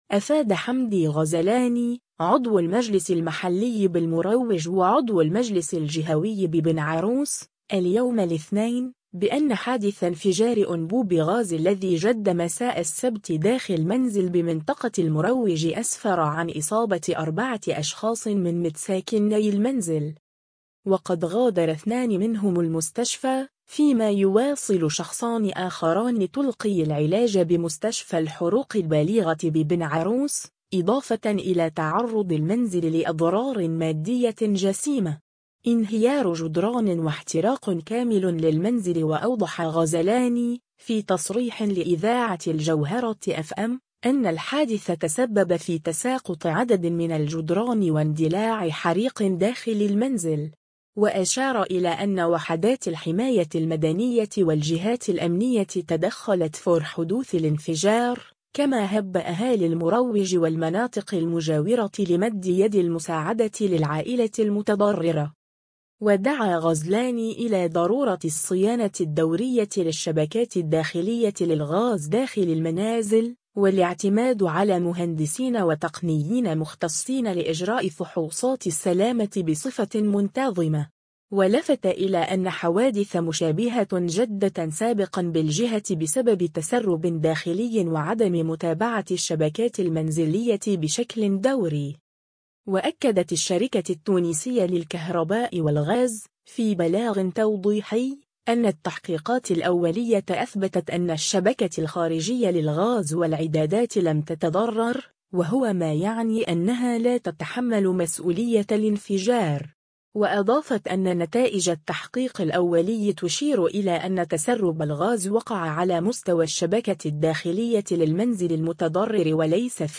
وأوضح غزلاني، في تصريح لإذاعة الجوهرة أف أم، أنّ الحادث تسبّب في تساقط عدد من الجدران واندلاع حريق داخل المنزل.